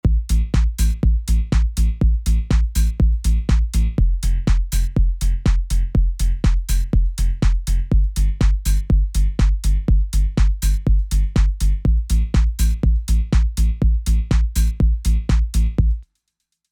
In my version I used an Argon8 so that I could have a sinewave as one oscillator and a more metallic waveform for the other oscillator. I played a typical octave bassline, with velocity routed to the level of the more metallic bass. I programmed it so that high velocity was only on the off-beats (I hope this all made sense).